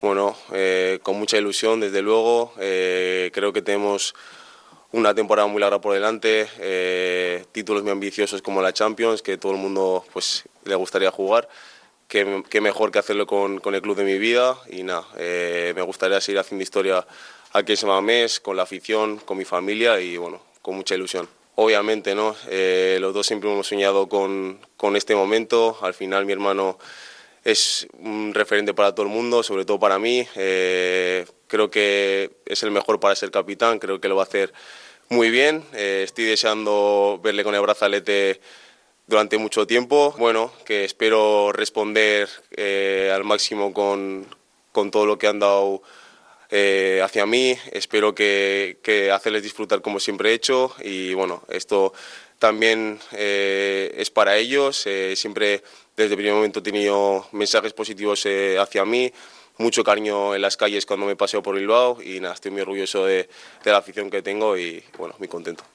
Junto al anuncio, han llegado las primeras declaraciones en varios meses. En ellas ha destacado lo ilusionado que está con la nueva temporada, con el objetivo de luchar la Champions League y el resto de competiciones posibles.
DECLARACIONES-NICO.mp3